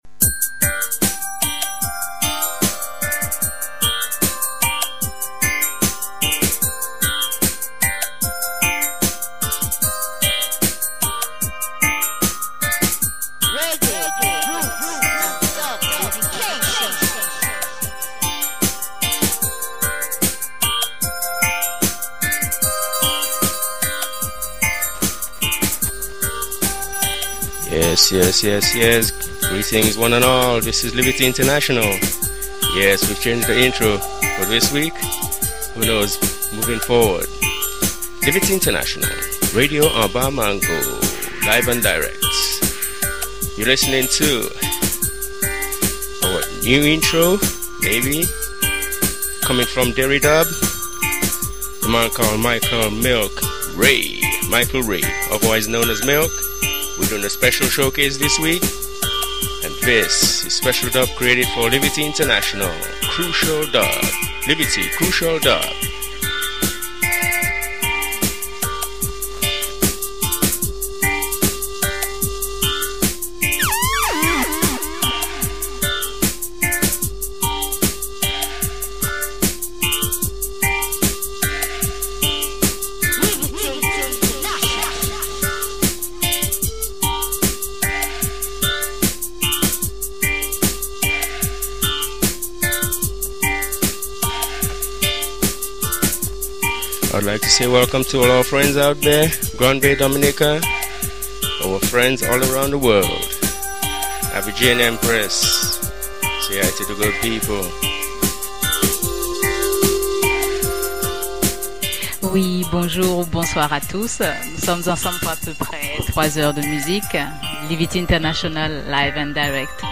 Live & Direct.